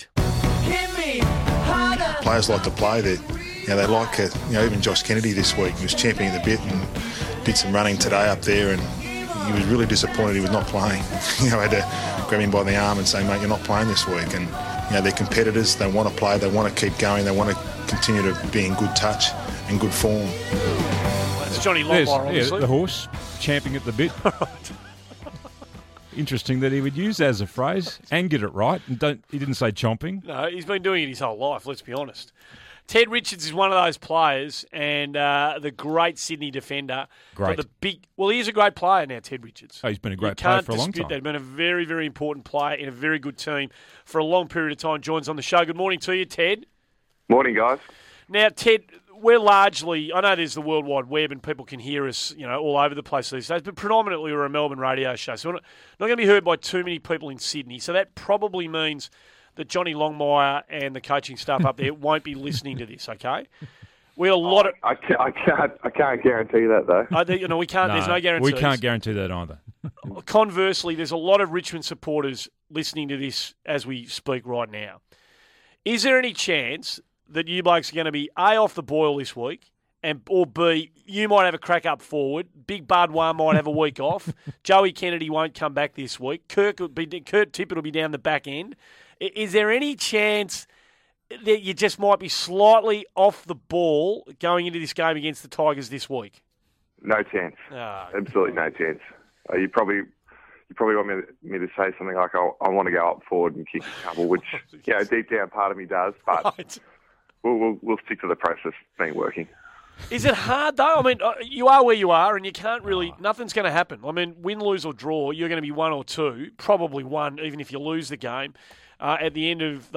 Sydney Swans defender Ted Richards appeared on 1116SEN's Morning Glory program on Tuesday August 26, 2014